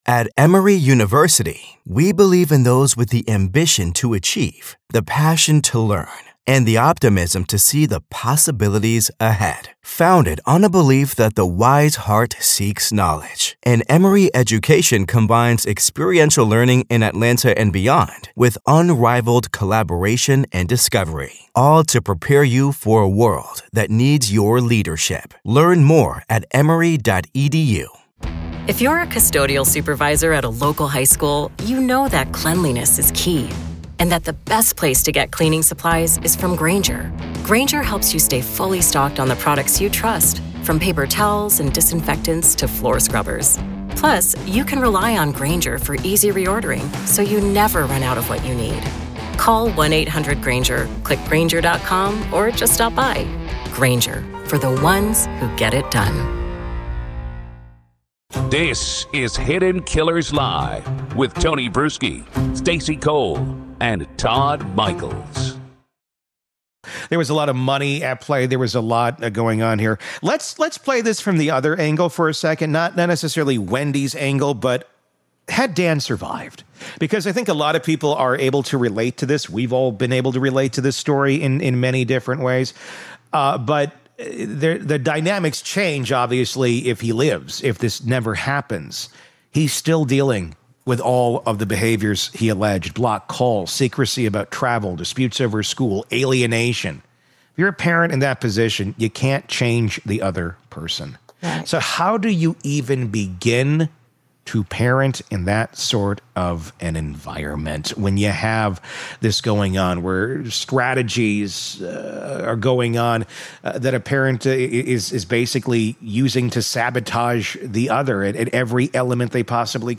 Far from sensational, this conversation is emotionally grounded and painfully honest.